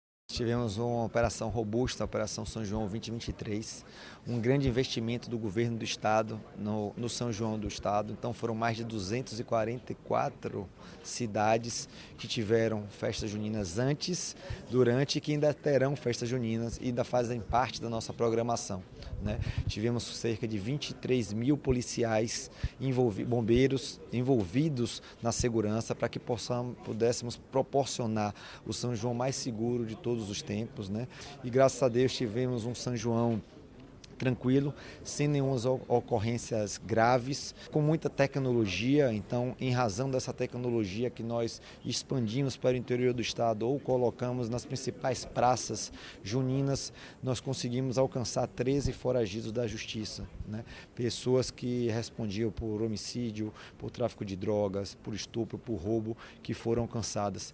Os dados foram apresentados nesta terça-feira (27), durante coletiva de imprensa realizada pela SSP, em Salvador.
🔊 Sonora secretário da Segurança Pública Marcelo Werner